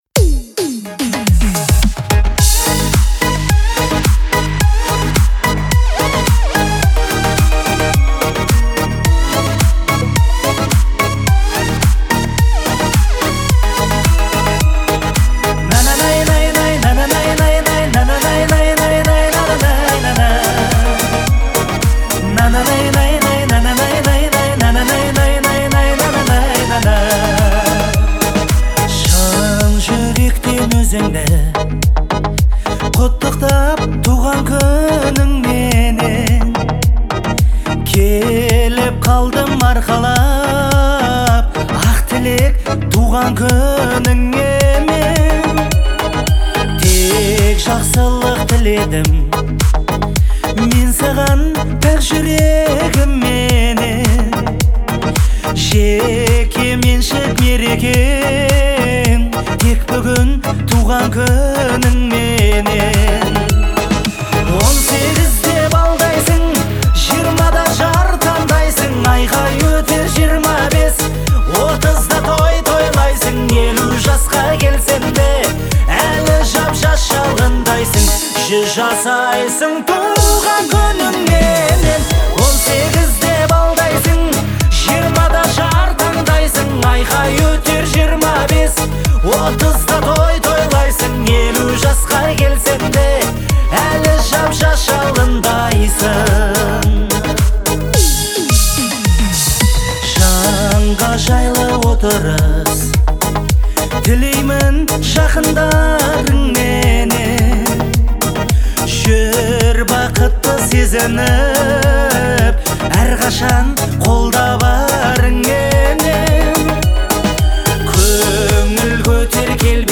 это яркая и эмоциональная песня в жанре поп